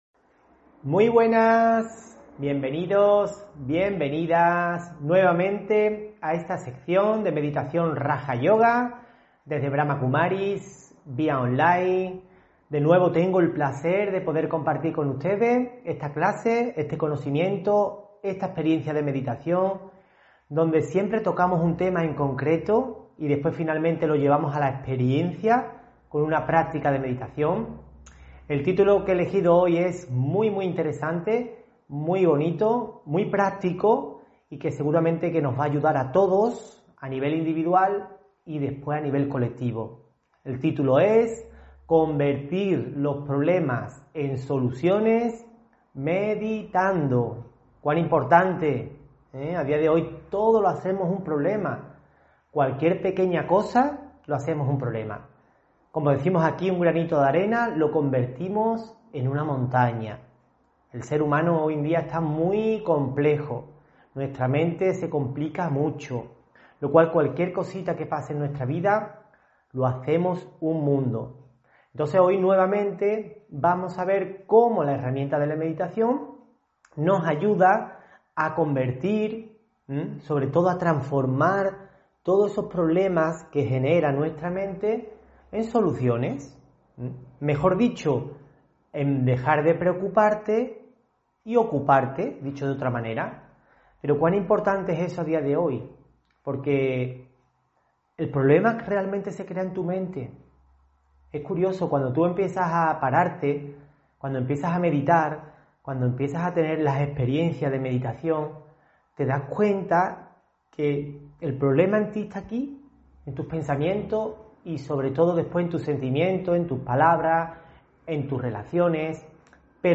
Meditación y conferencia: Convertir los problema en soluciones meditando (17 Noviembre 2021)